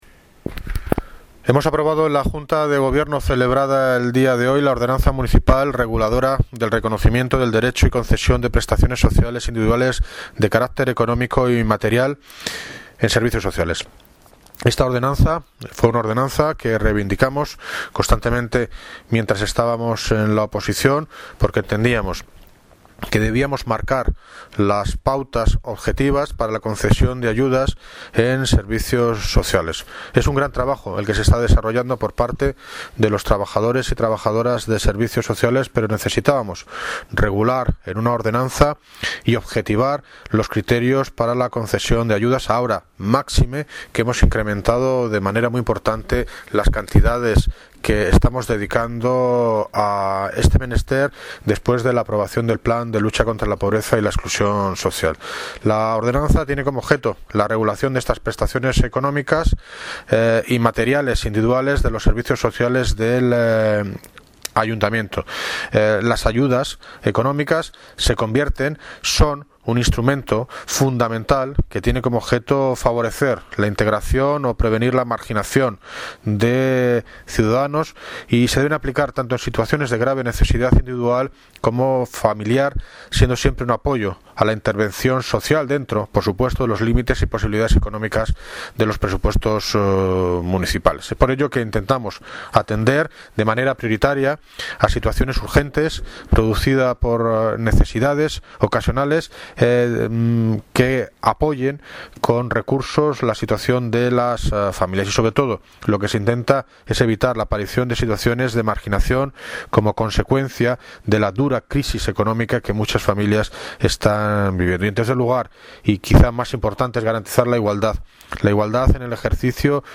Audio - David Lucas (Alcalde de Móstoles) Sobre Ordenanza de Prestaciones Económicas